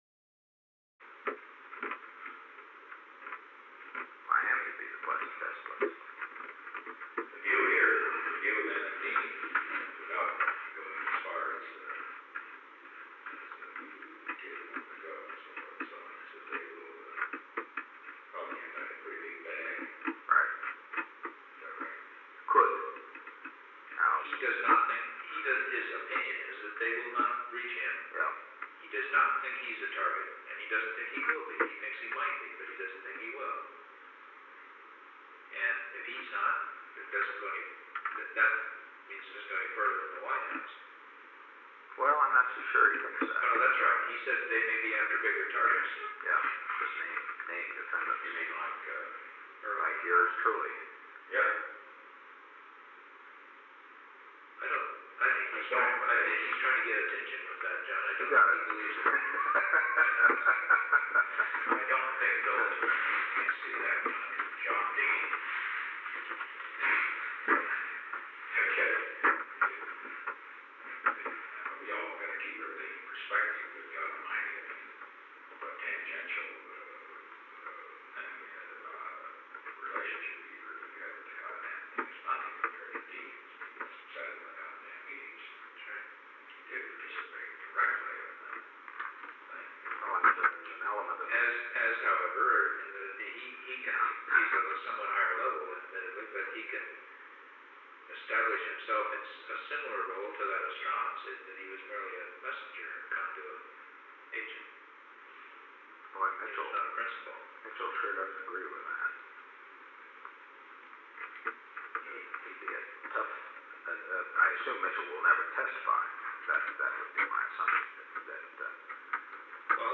Conversation No. 896-5 Date: April 14, 1973 Time: Unknown between 2:13 pm between 3:55 pm Location: Oval Office H. R. (“Bob”) Haldeman met with an unknown man [John D. Ehrlichman ?].
Secret White House Tapes